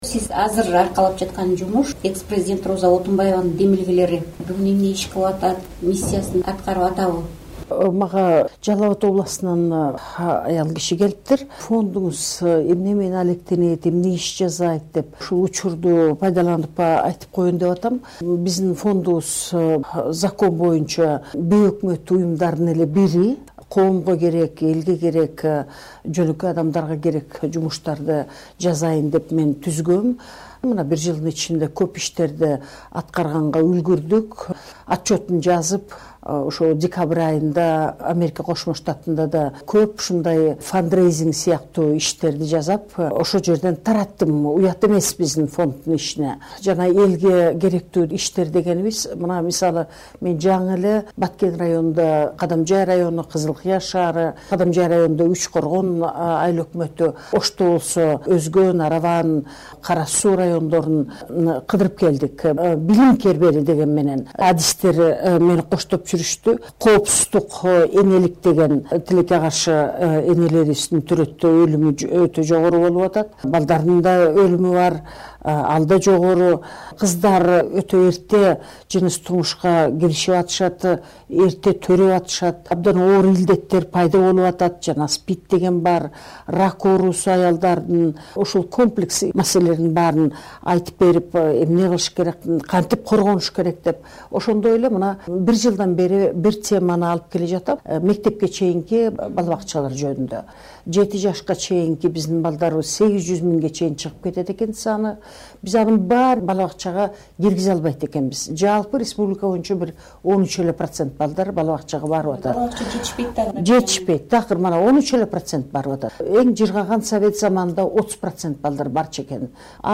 Роза Отунбаева менен маектин 2-бөлүгү